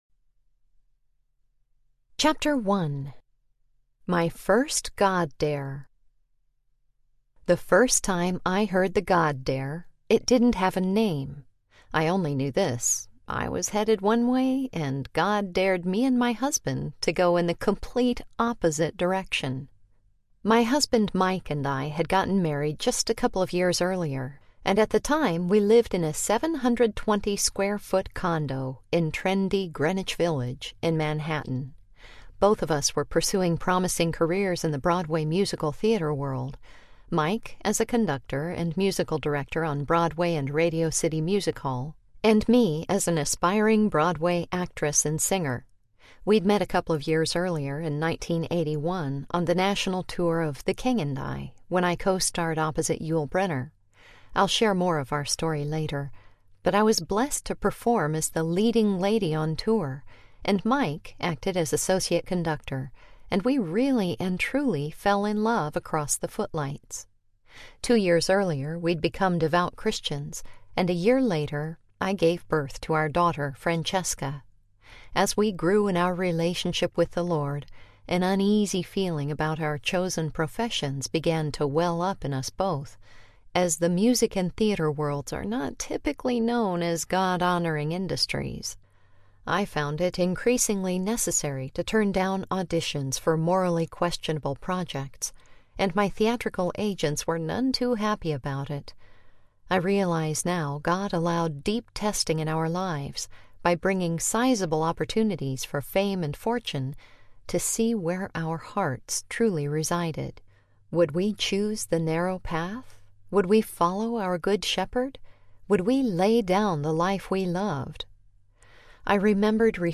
The God Dare Audiobook